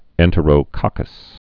(ĕntə-rō-kŏkəs)